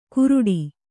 ♪ kuruḍi